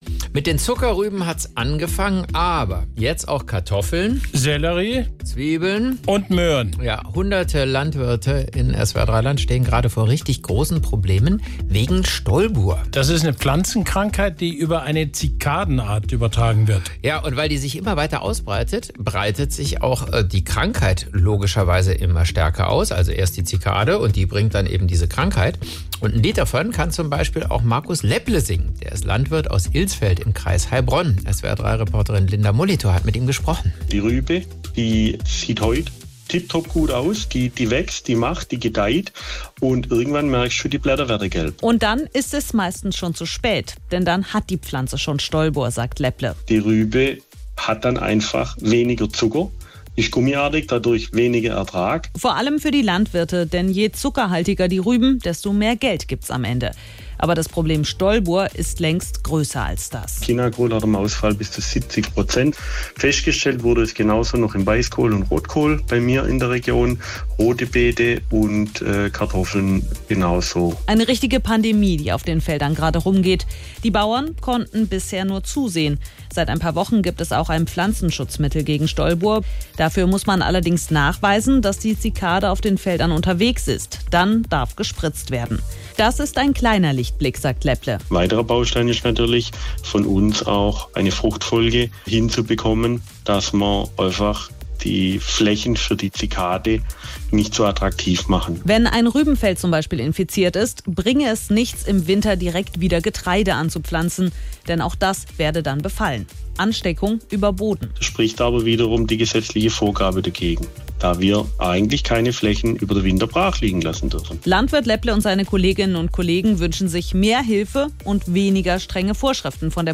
Nachrichten
Landwirt